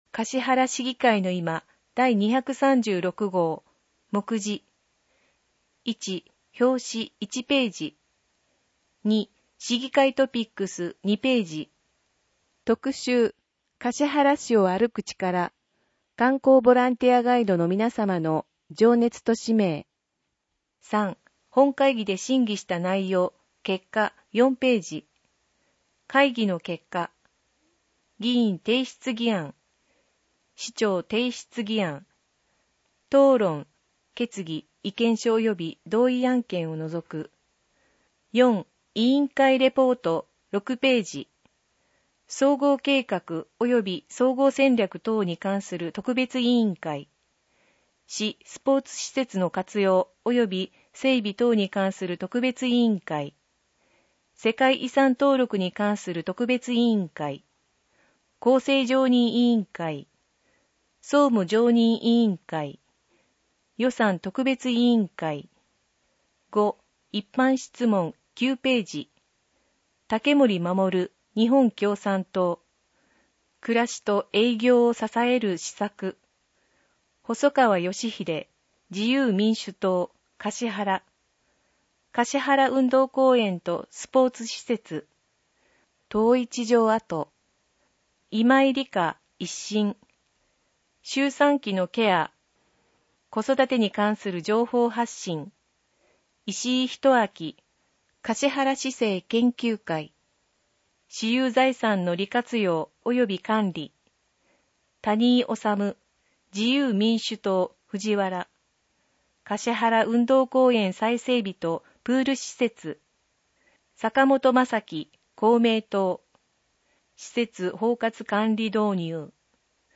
音訳データ